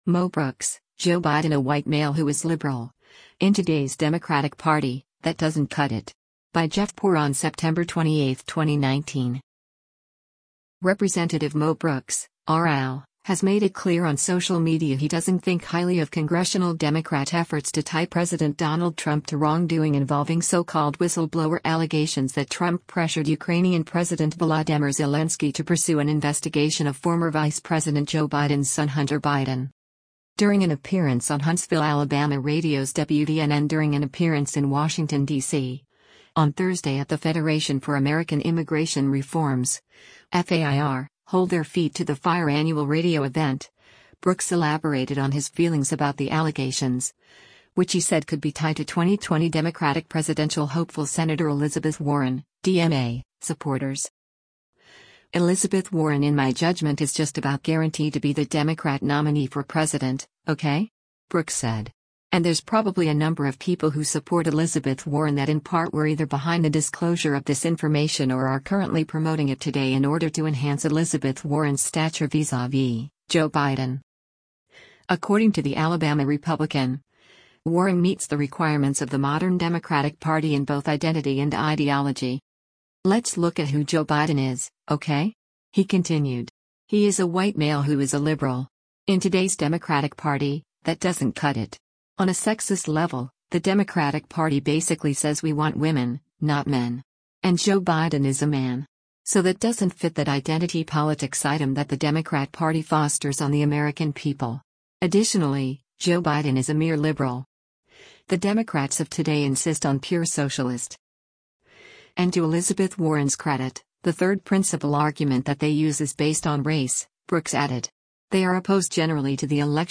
During an appearance on Huntsville, AL radio’s WVNN during an appearance in Washington, D.C. on Thursday at the Federation for American Immigration Reform’s (FAIR) Hold Their Feet to the Fire annual radio event, Brooks elaborated on his feelings about the allegations, which he said could be tied to 2020 Democratic presidential hopeful Sen. Elizabeth Warren (D-MA) supporters.